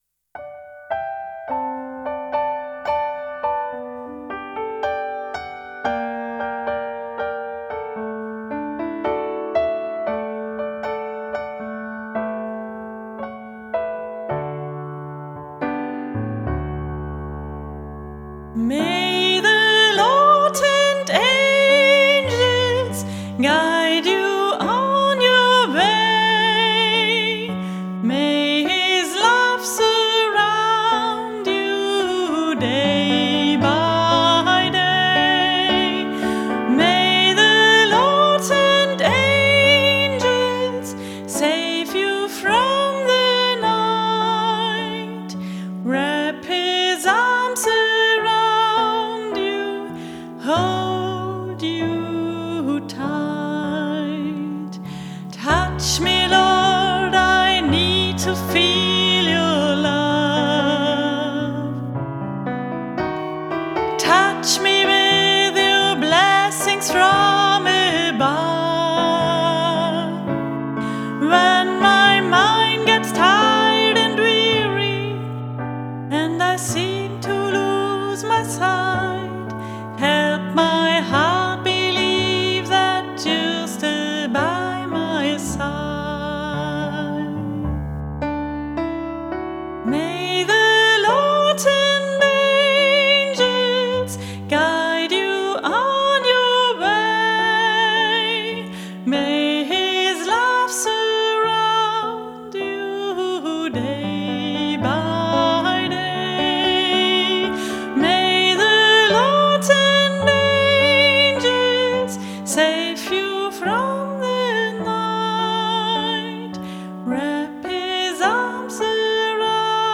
Gesang, Klavier und mehr.